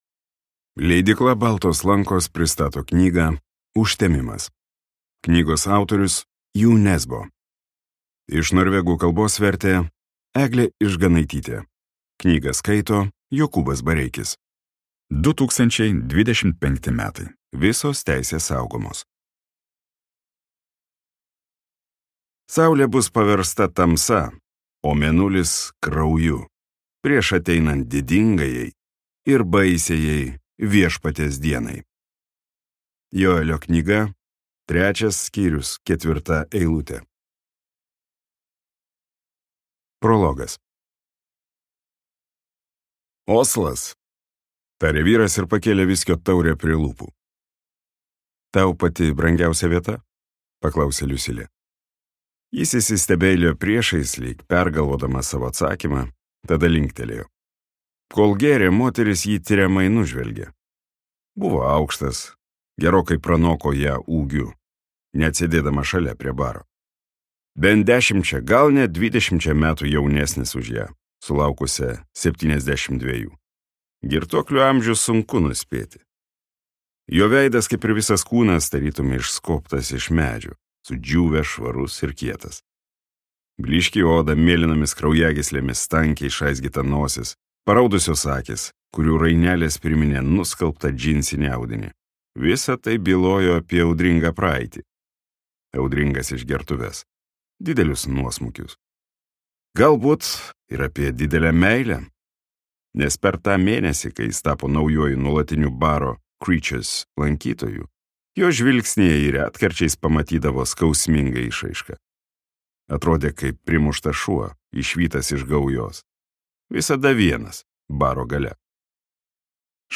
Skaityti ištrauką play 00:00 Share on Facebook Share on Twitter Share on Pinterest Audio Užtemimas.